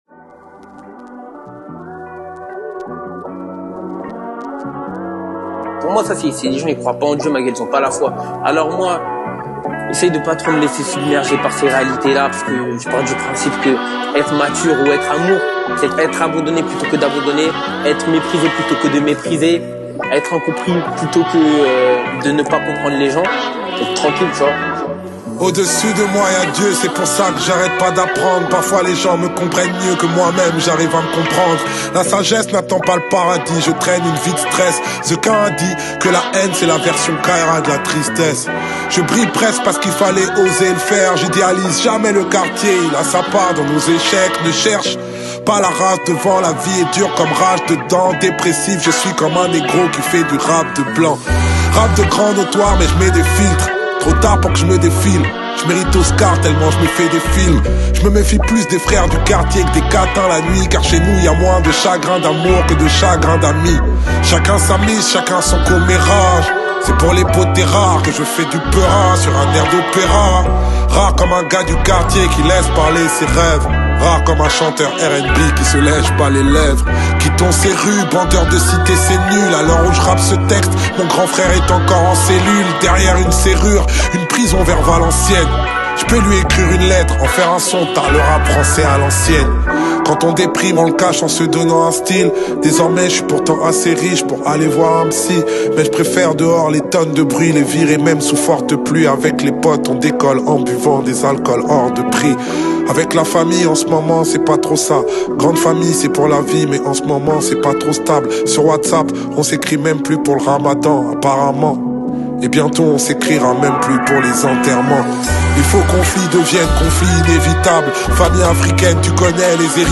17/100 Genres : french rap, french r&b Écouter sur Spotify